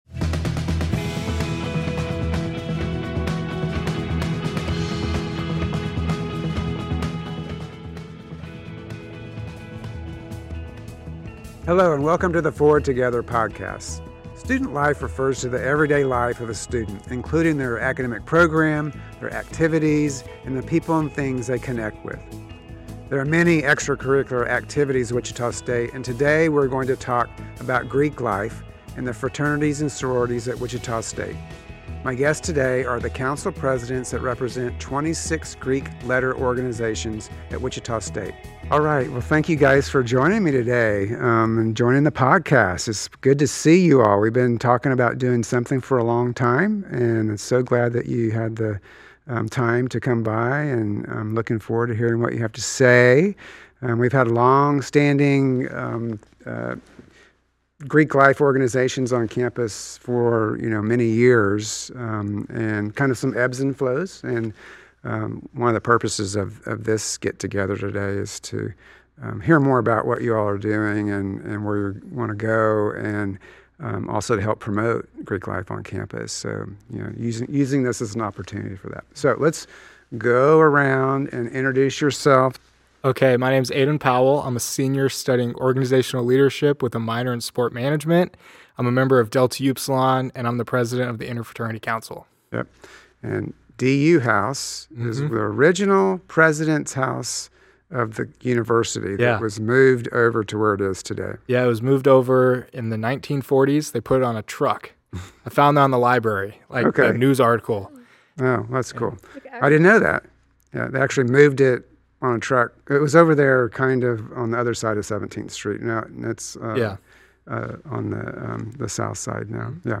Join President Muma when he sits down with the council presidents who represent 26 Greek-letter organizations at WSU. Tune in for an inside look at how Greek life shapes our campus community and student experiences.